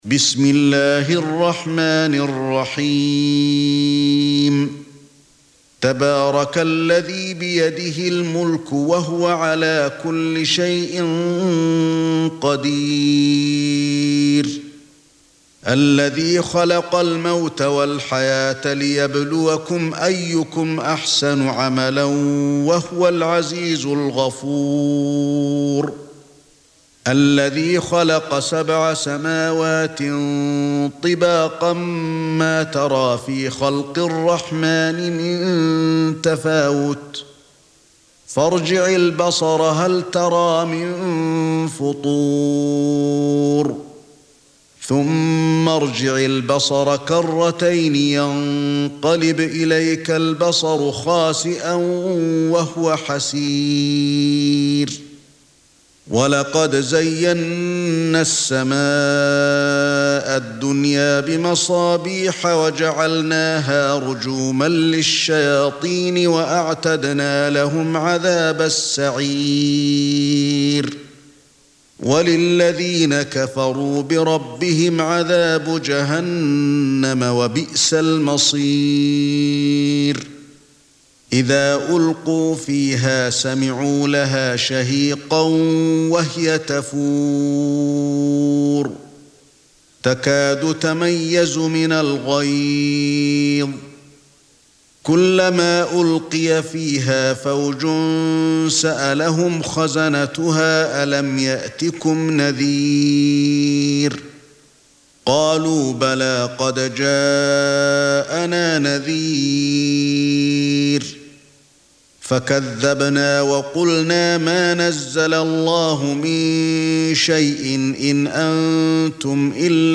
Surah Repeating تكرار السورة Download Surah حمّل السورة Reciting Murattalah Audio for 67. Surah Al-Mulk سورة الملك N.B *Surah Includes Al-Basmalah Reciters Sequents تتابع التلاوات Reciters Repeats تكرار التلاوات